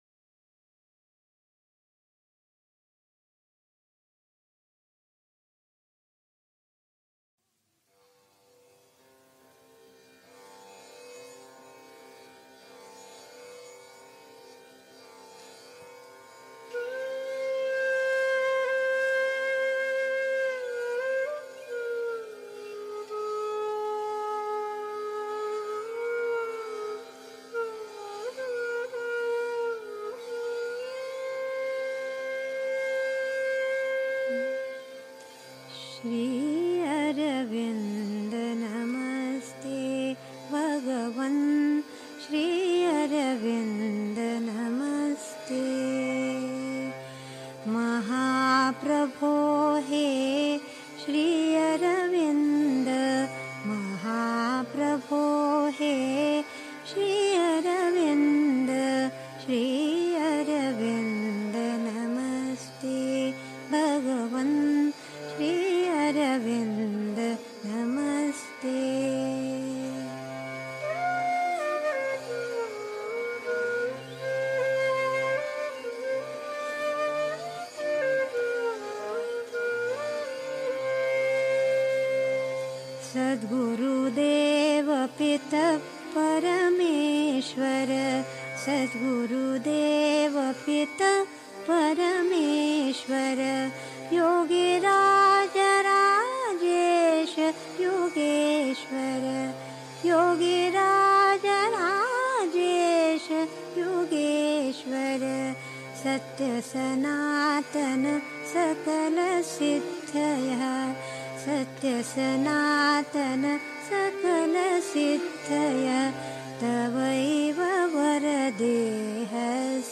1. Einstimmung mit Musik. 2. Eine der bedeutendsten Bewegungen des Yoga (Sri Aurobindo, CWSA Vol. 12, p. 345) 3. Zwölf Minuten Stille.